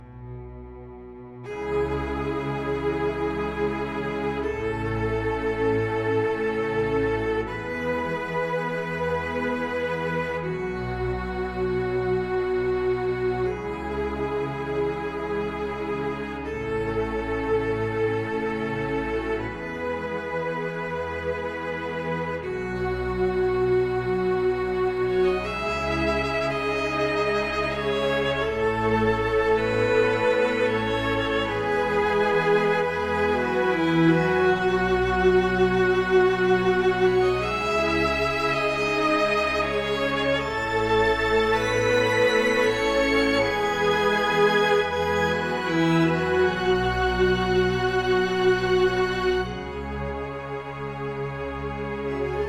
Arranjo de orchestra
Tive essa inspiração do espírito santo de lançar uma orchestra no refrão do hino
01-Infinito-amor-de-Deus-Refrao-Orcherstra.1.mp3